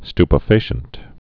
(stpə-fāshənt, sty-)